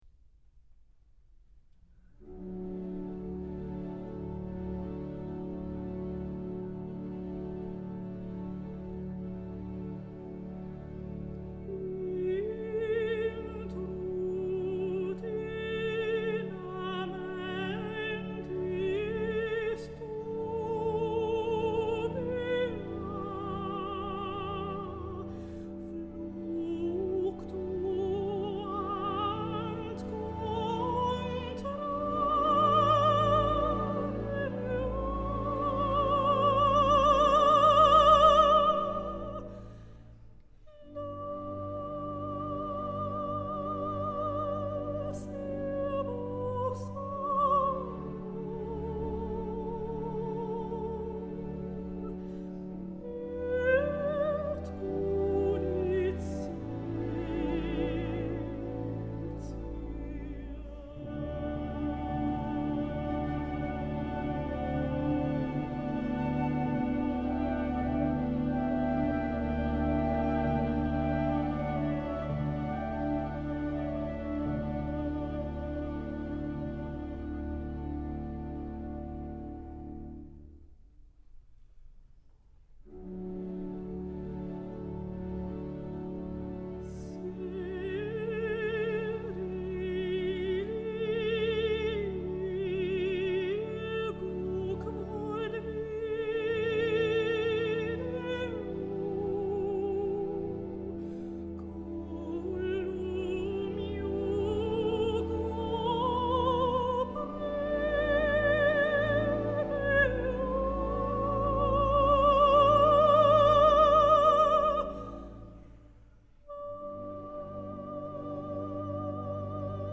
secular cantata